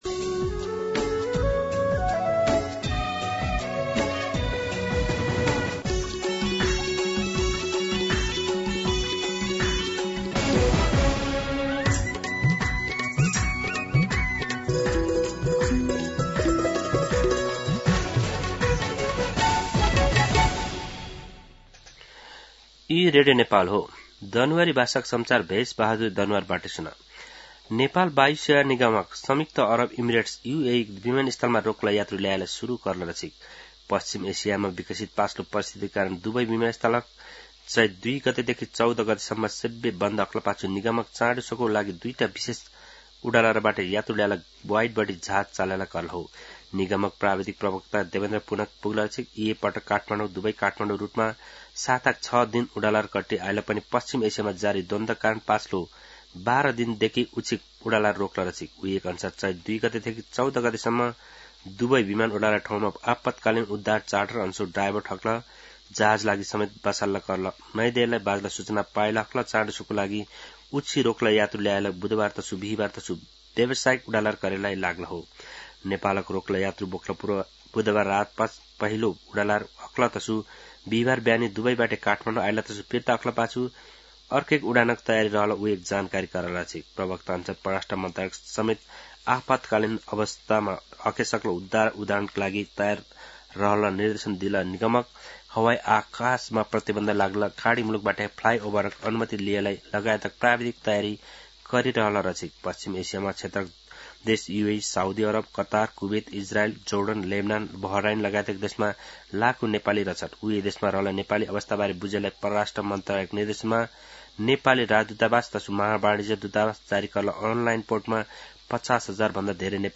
दनुवार भाषामा समाचार : २८ फागुन , २०८२